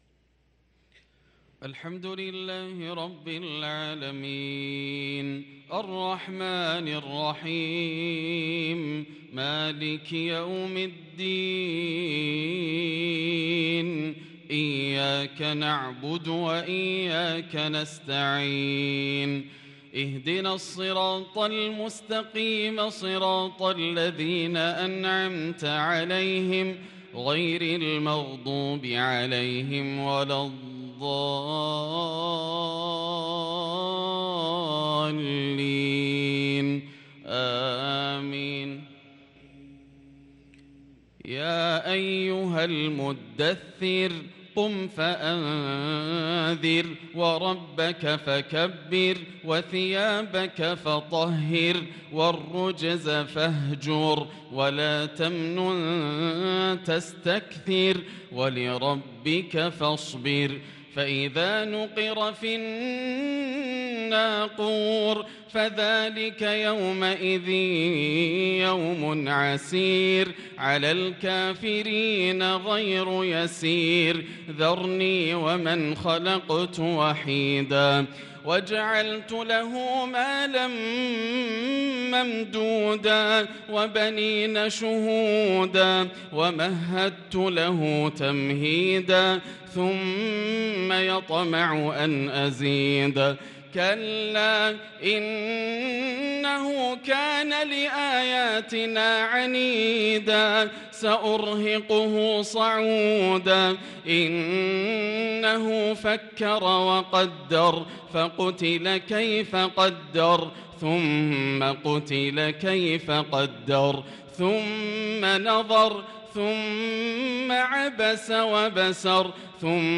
صلاة الفجر للقارئ ياسر الدوسري 22 ذو القعدة 1443 هـ
تِلَاوَات الْحَرَمَيْن .